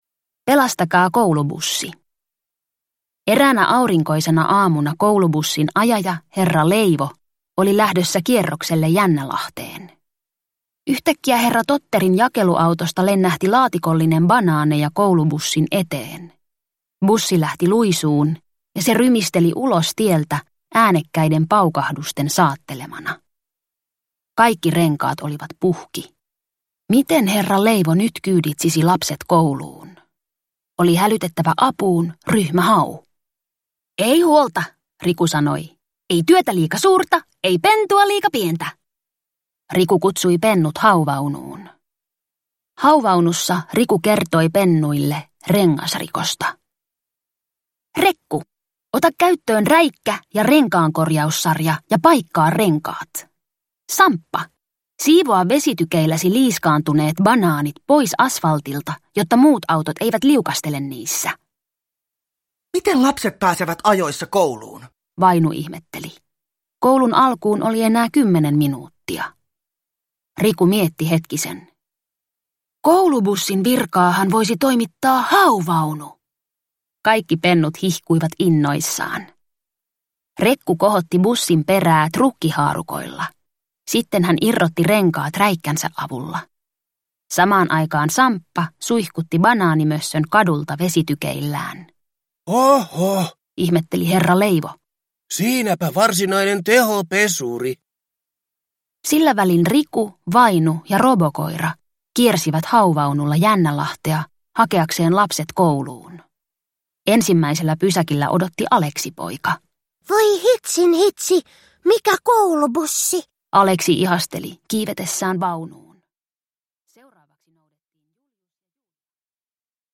Ryhmä Hau - parhaat tarinat – Ljudbok – Laddas ner